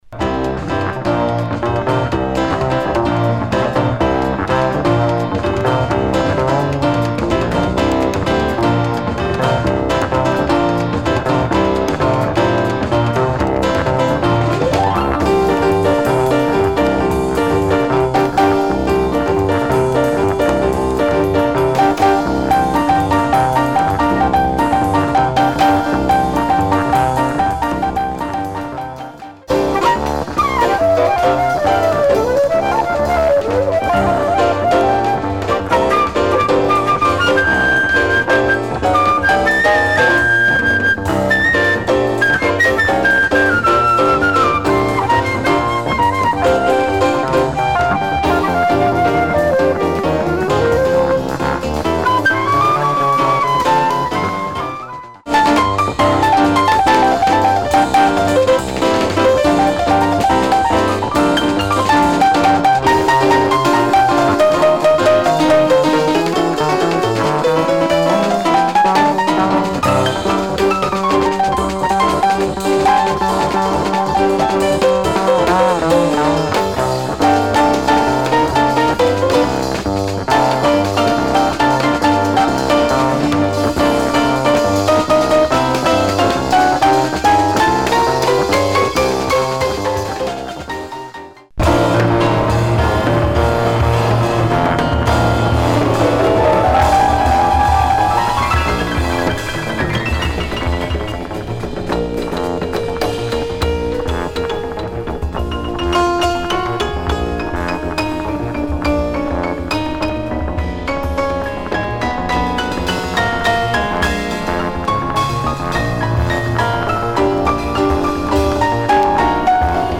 dancefloor jazz
the beautiful modal pieces